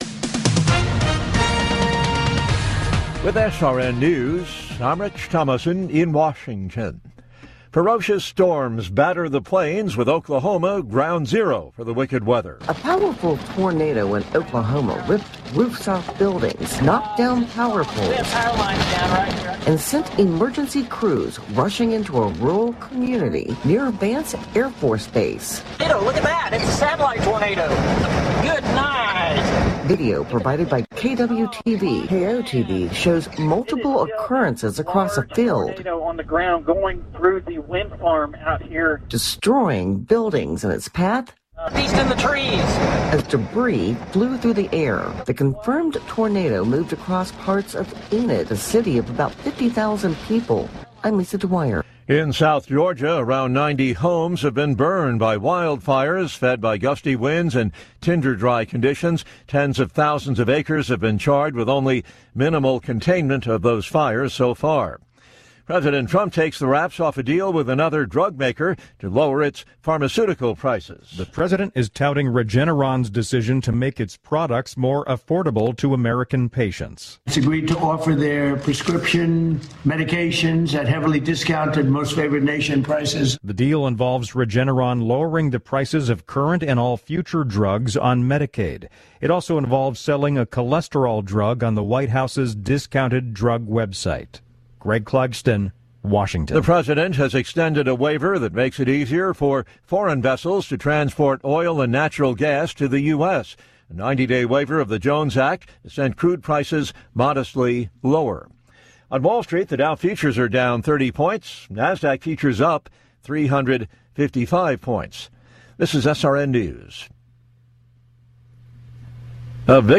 News stories as heard on SRN Radio News.